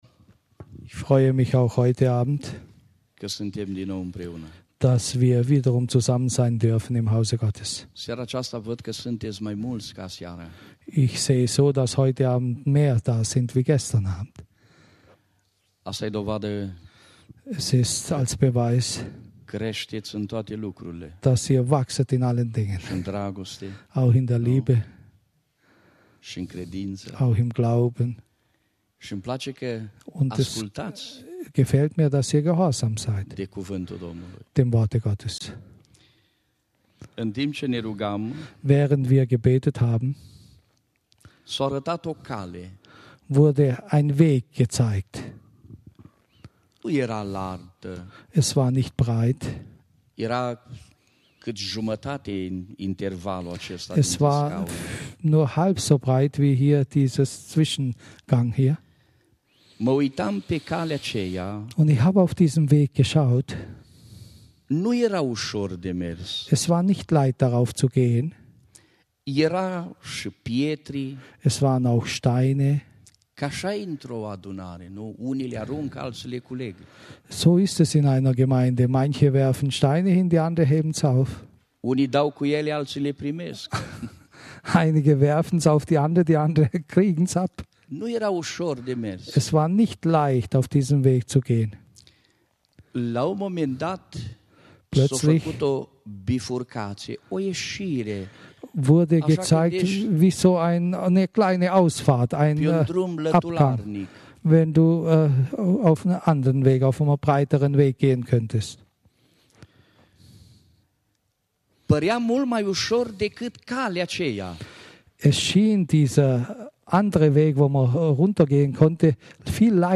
Predigt
im Christlichen Zentrum Villingen-Schwenningen